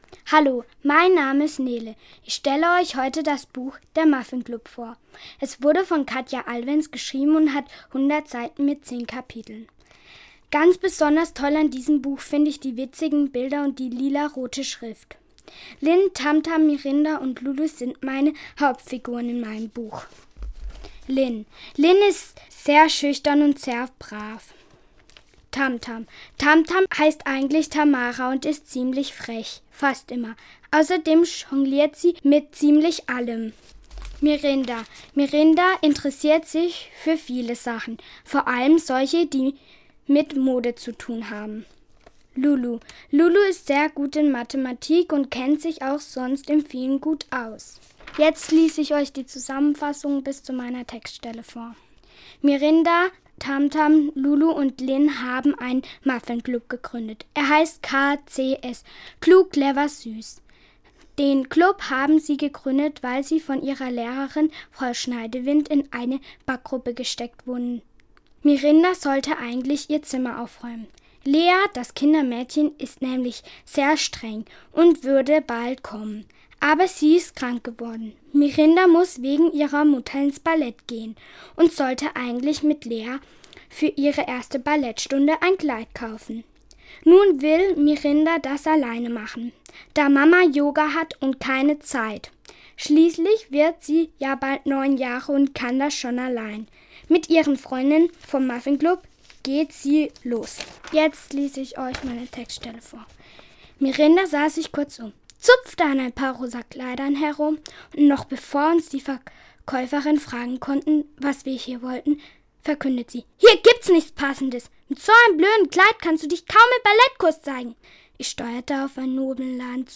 Buchvorstellung: „Der Muffin-Club“ von Katja Alves – Podcastarchiv der PH-Freiburg